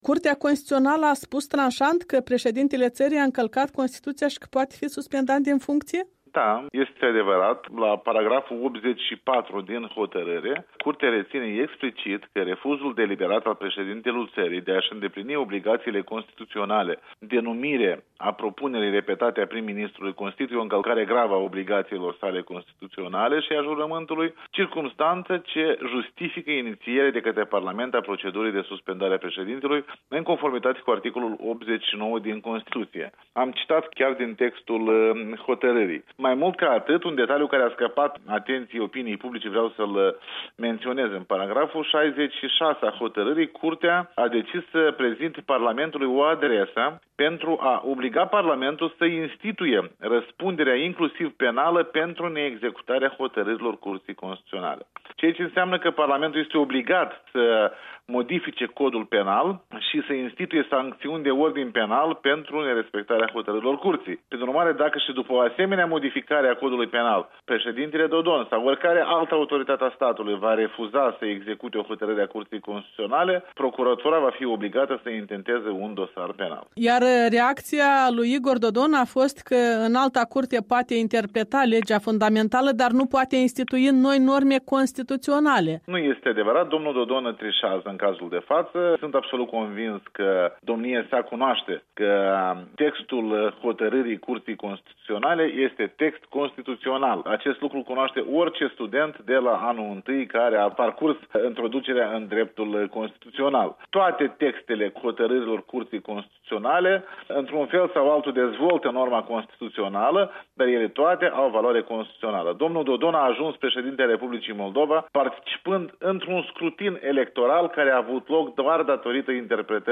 Un interviu cu fostul preşedinte al Curţii Constituţionale.
Un interviu cu Alexandru Tănase